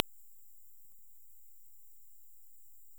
Eastern Forest Bat – Full spectrum bat calls, NSW, Australia
Vespadelus pumilus VEPU
Characteristic frequency 50 up to 58 kHz. Curved often with prominent up-sweeping tail.
Here is a typical high frequency bat with clear up-tails.